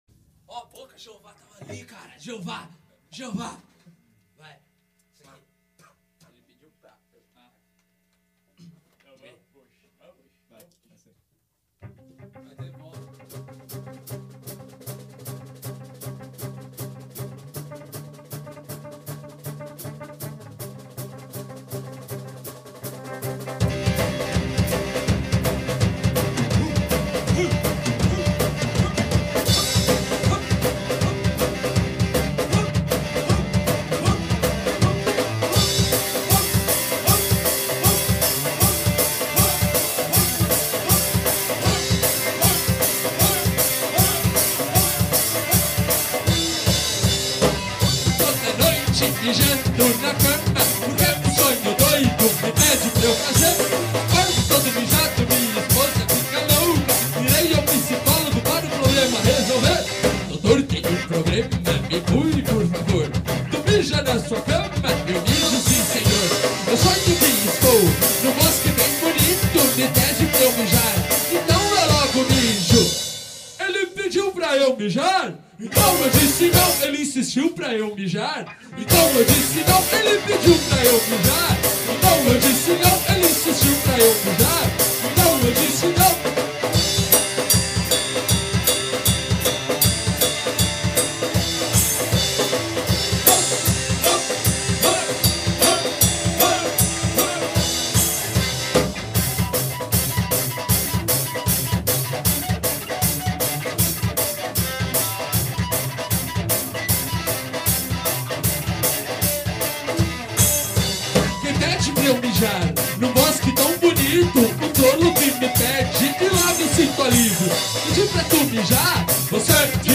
EstiloParódia / Comédia